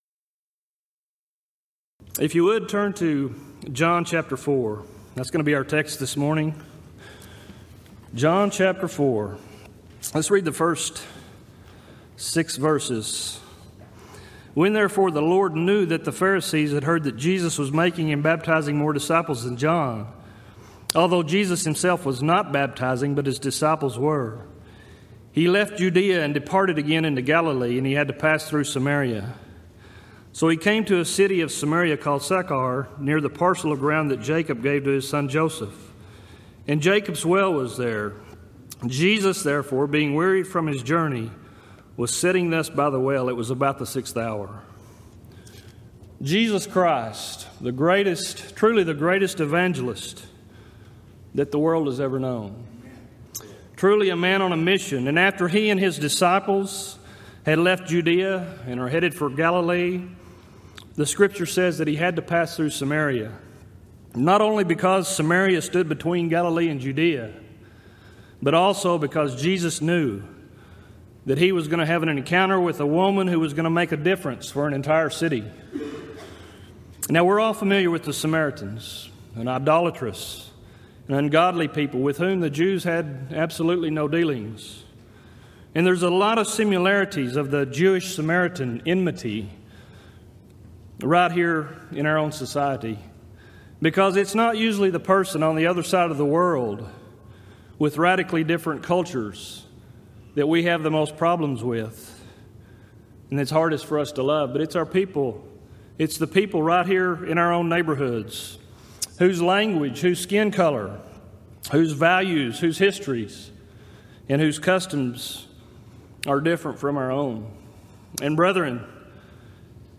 Event: 24th Annual Gulf Coast Lectures
lecture